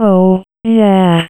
OH YEAH3.wav